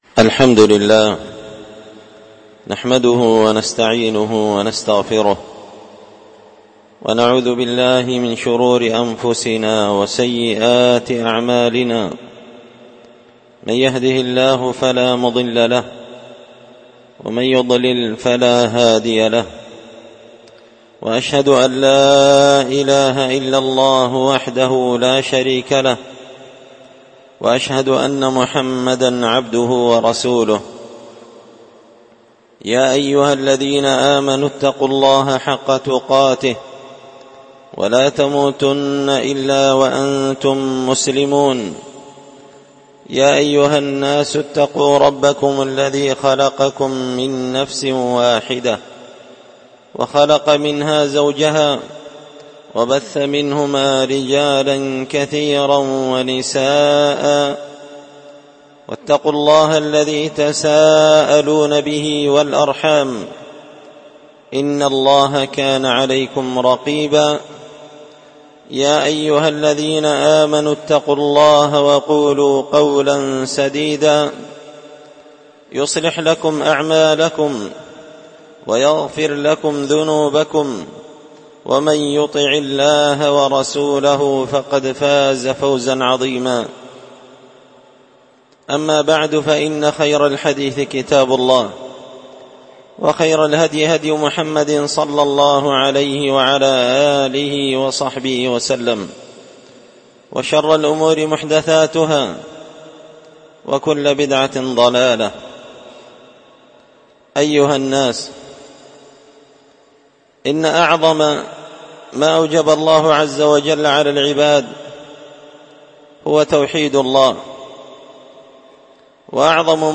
خطبة جمعة بعنوان – تنبيهات على خطر التهاون بالصلوات
دار الحديث بمسجد الفرقان ـ قشن ـ المهرة ـ اليمن